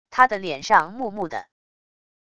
他的脸上木木的wav音频生成系统WAV Audio Player